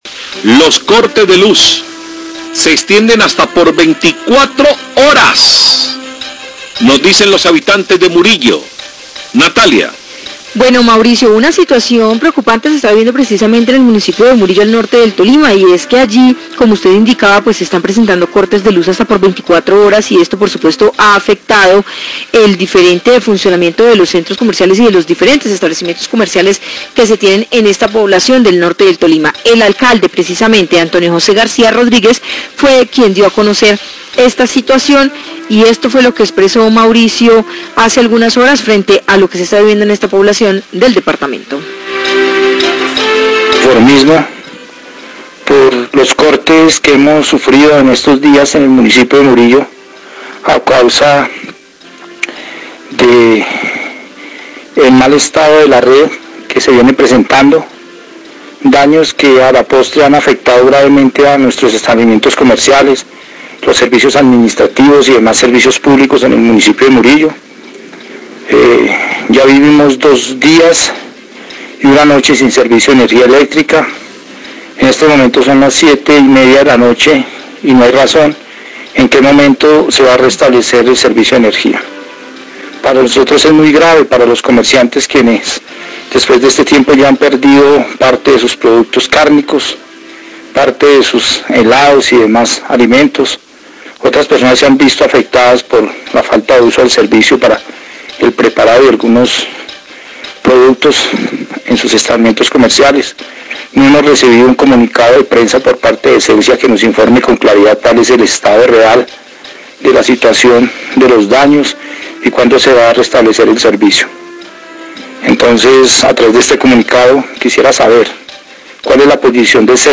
"Cortes de energía de hasta 24 horas están afectando gravemente a Murilo" Alcalde del municipio
Radio